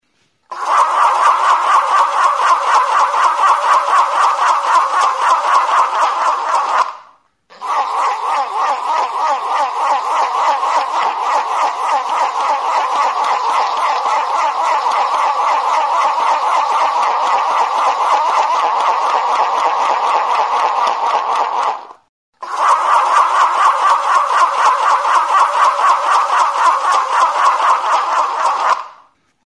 Membranófonos -> Friccionados / frotados -> Cuerda
Grabado con este instrumento.
Jirabirako igurtzitako danborra; Tambor de fricción; Friction drum
Kartoizko tutu zilindrikoa du.